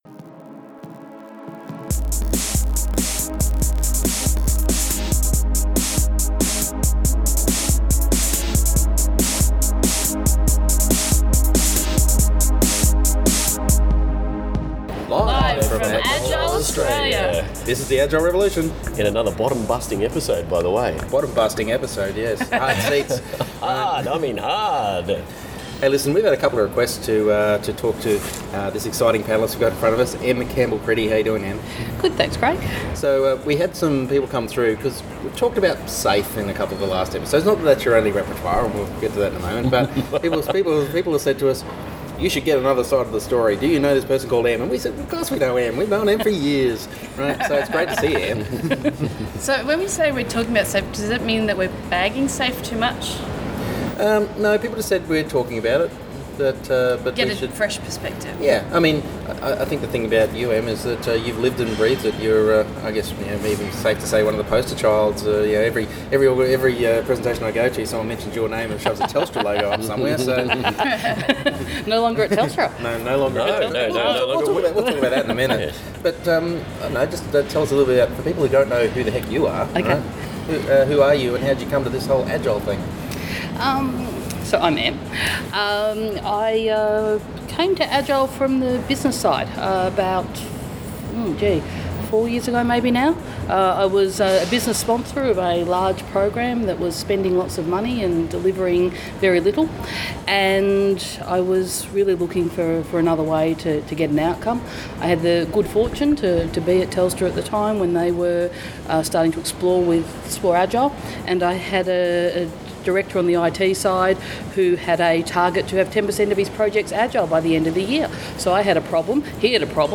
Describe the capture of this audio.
At Agile Australia 2014 in Melbourne